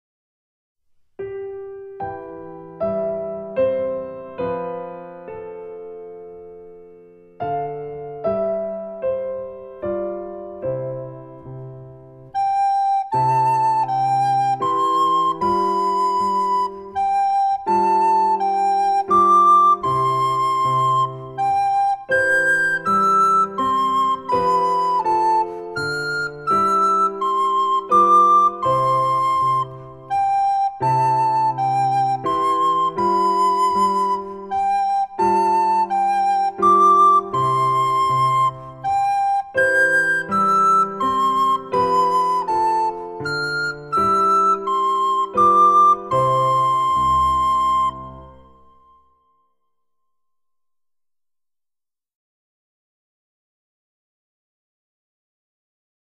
４年生 リコーダーの演奏
🎵陽気な船長はこちら４年生 リコーダー ♪陽気な船長 🎵オーラリーはこちら４年生 リコーダー ♪オーラリー 🎵ハッピーバスディトゥーユーはこちら４年生 リコーダー ♪ハッピーバスディトゥーユー